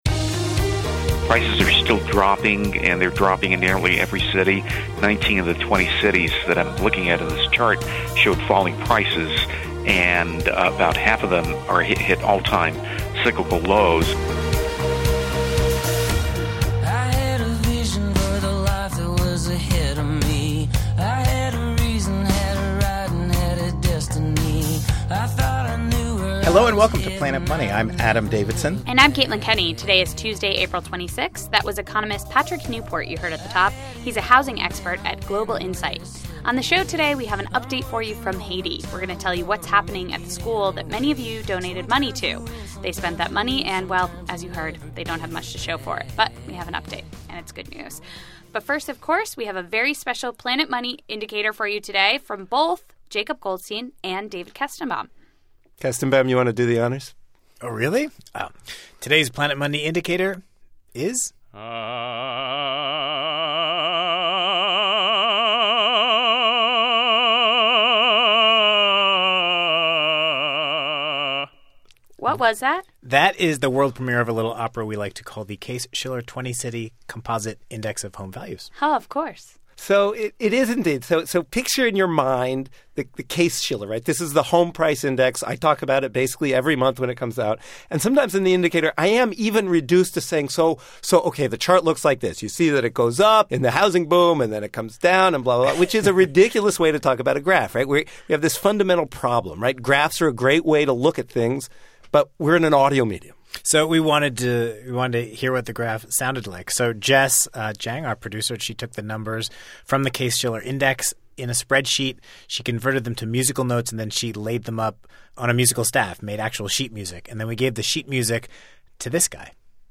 Also on the podcast, a very special Planet Money Indicator: A decade of U.S. home prices, sung as opera.